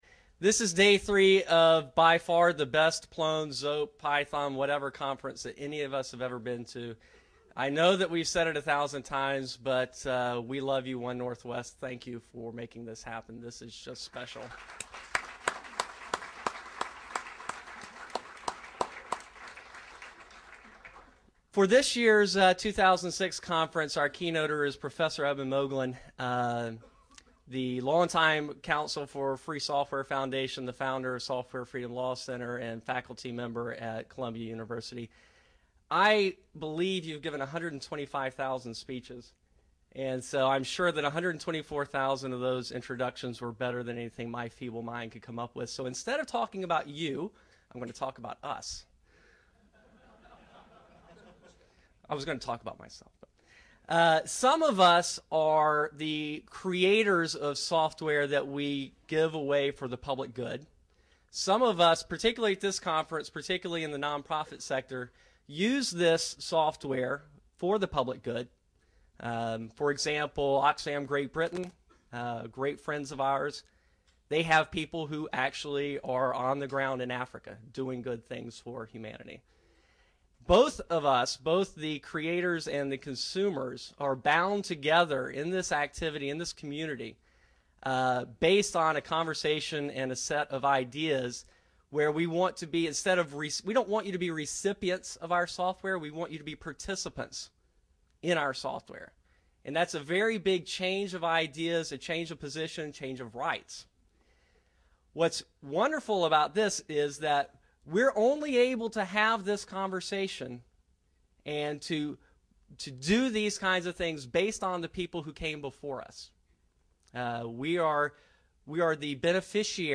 Eben Moglen, chairman of the Software Freedom Law Center, gives a keynote at the October 2006 Plone conference in Seattle.